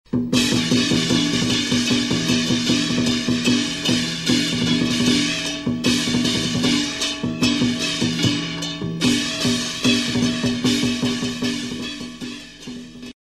锣鼓声
【简介】： 节日喜庆、敲锣打鼓